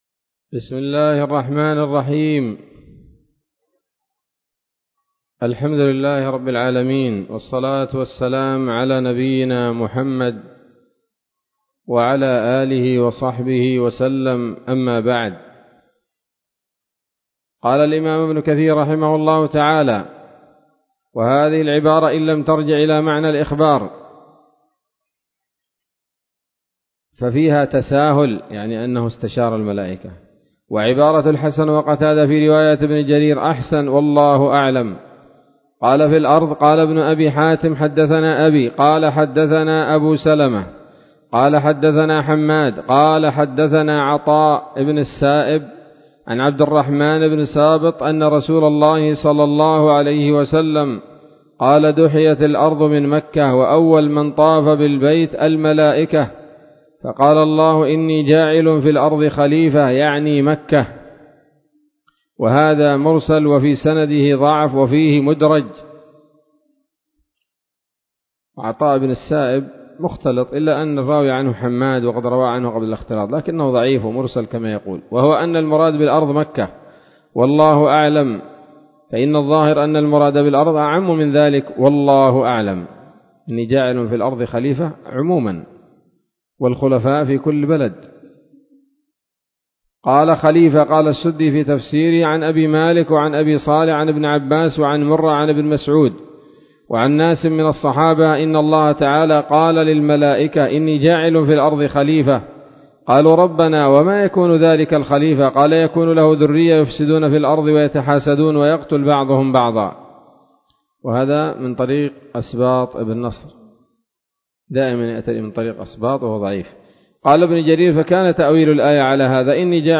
الدرس الرابع والثلاثون من سورة البقرة من تفسير ابن كثير رحمه الله تعالى